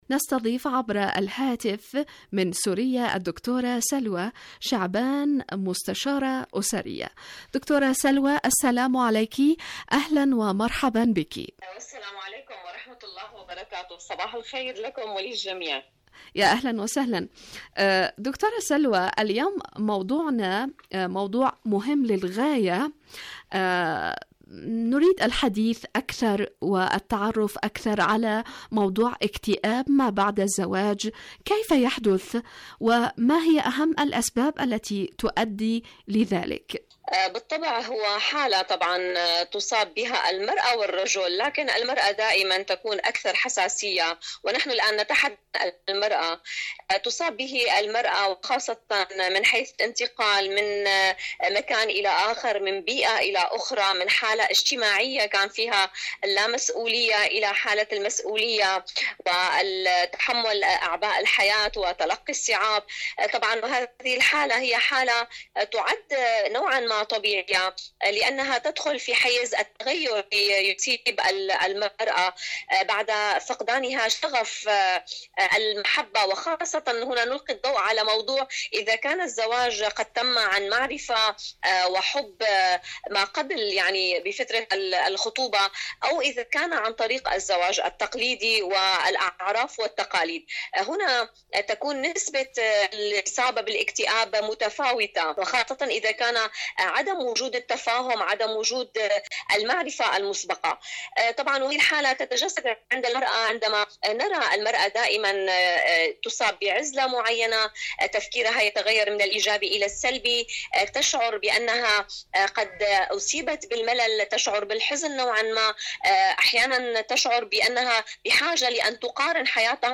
مقابلات مقابلات إذاعية برنامج عالم المرأة المرأة الكآبة كآبة المرأة كآبة الزوجة كآبة المرأة بعد زواجها اهتمام بالاسرة في المجتمع شاركوا هذا الخبر مع أصدقائكم ذات صلة مواقف طهران من تهديدات ترامب..